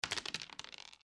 dice3.mp3